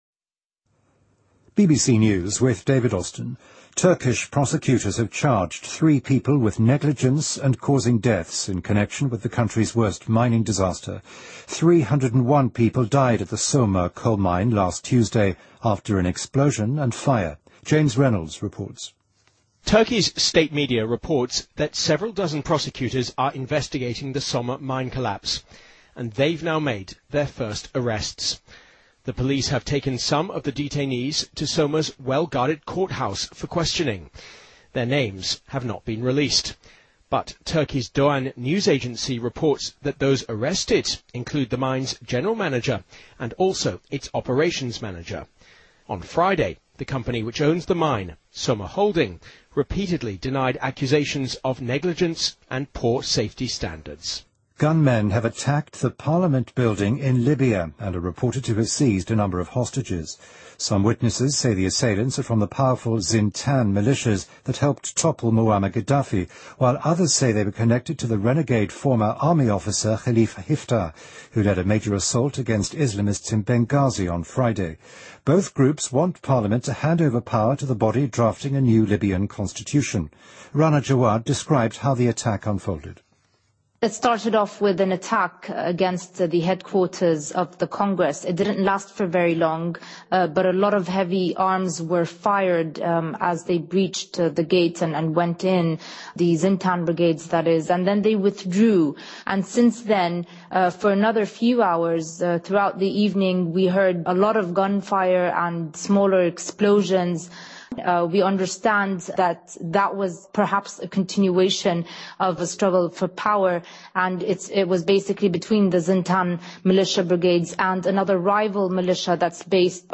BBC news,武装分子袭击利比亚议会大楼劫持多名人质